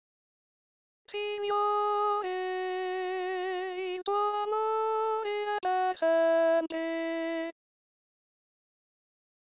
Messa Domenica 23 agosto 2020
SALMO RESPONSORIALE